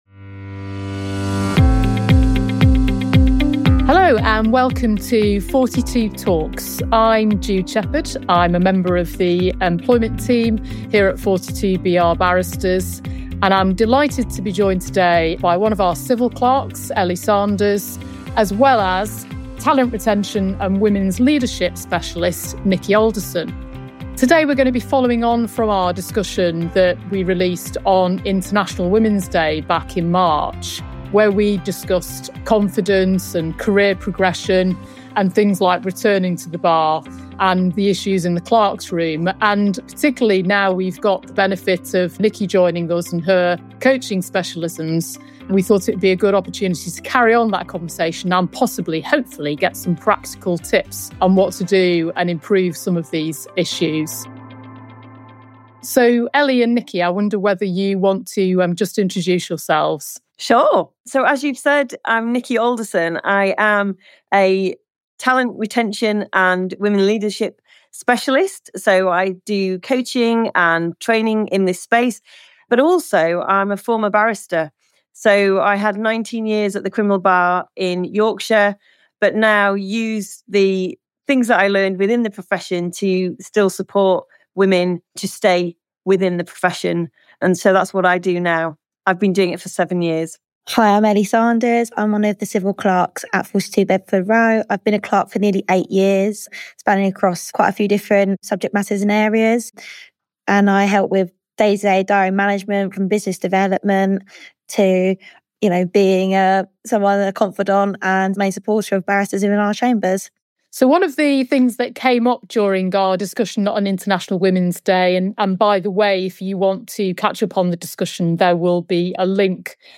This engaging discussion dives into the complex and relatable topic of imposter syndrome, and how it affects women across roles at the Bar – from clerks to barristers.